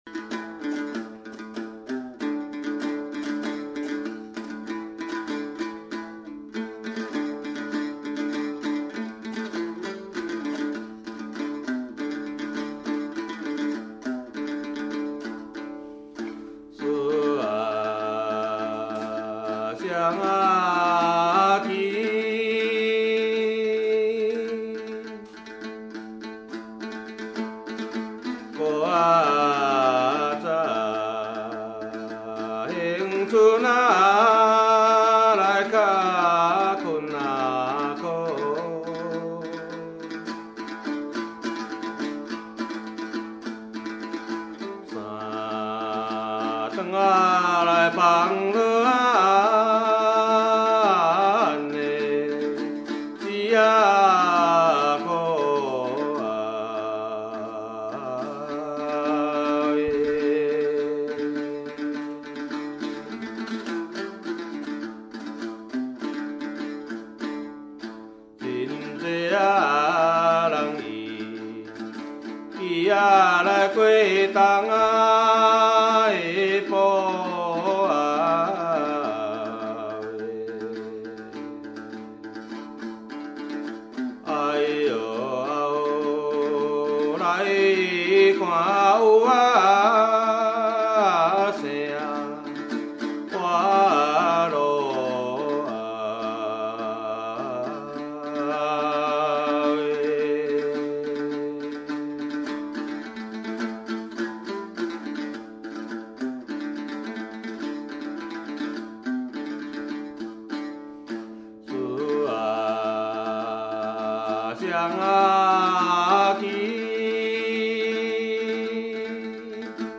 ◎制作群 ： 演出：月琴,壳仔弦｜
台湾民歌